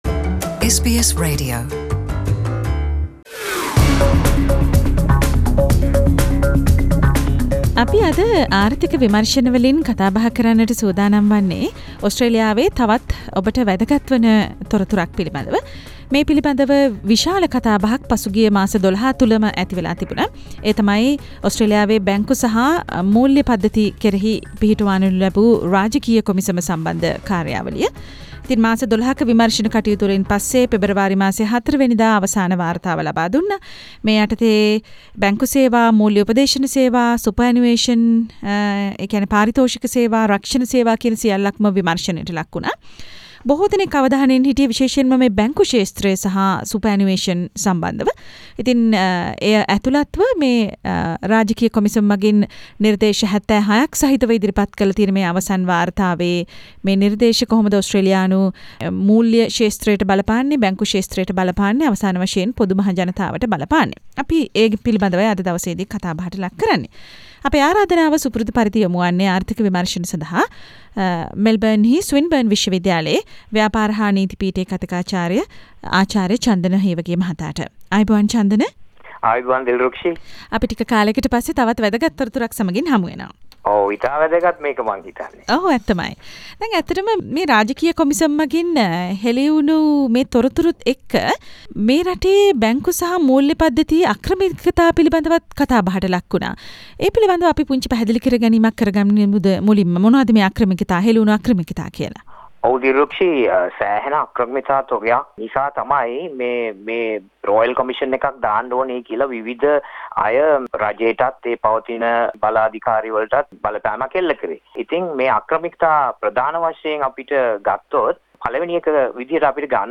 ඕස්ට්‍රේලියාවේ බැංකු සහ මුල්‍ය පද්දතිය විමර්ශනයට පත්කළ රාජකීය කොමිසමේ අවසන් වාර්තාව පසුගියදා එළිදැක්වුනා. එම අවසන් වාර්තාවේ නිර්දේශ අතරින් සාමාන්‍ය ජනතාවට වඩාත්ම බලපාන කරුණු මෙම සාකච්චාවෙන් විමර්ශනය කෙරේ.